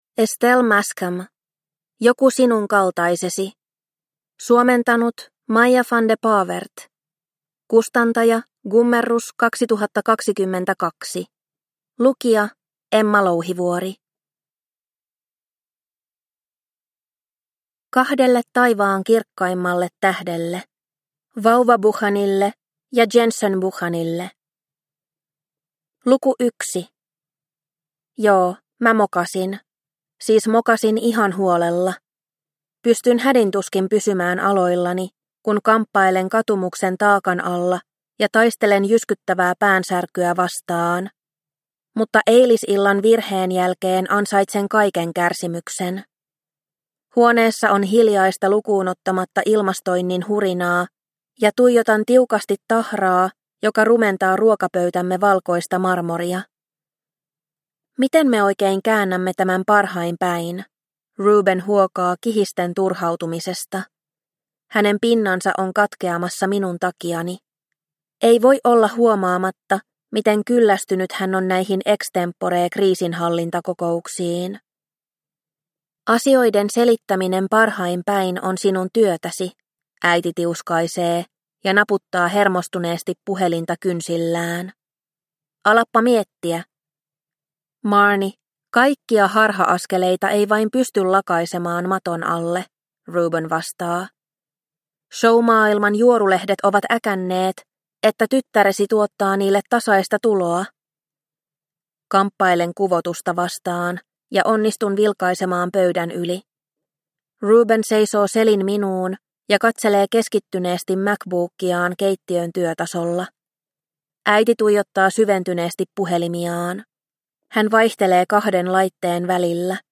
Joku sinun kaltaisesi – Ljudbok – Laddas ner